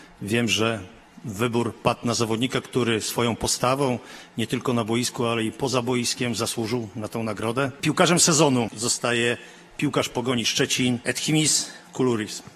Ogłosił podczas gali Canal +, prezes Polskiego Związku Piłki Nożnej, Cezary Kulesza.